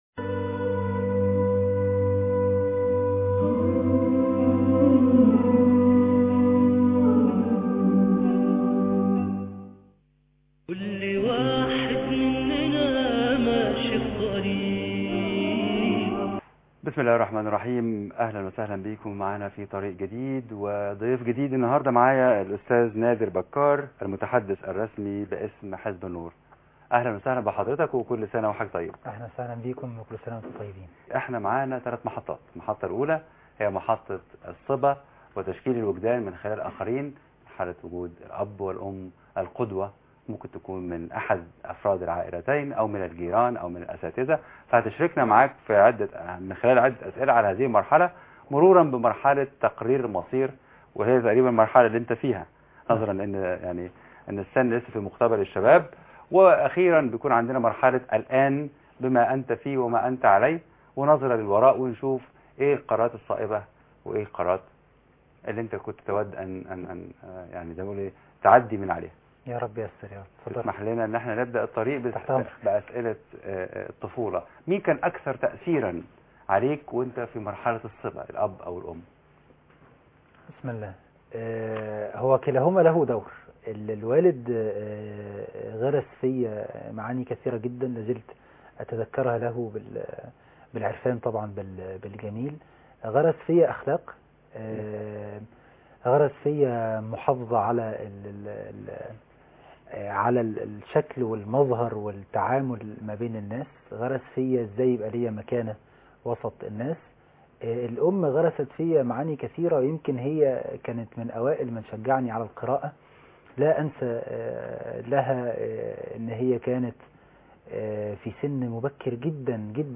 لقاء مع نادر بكار (8/8/2012) الطريق - قسم المنوعات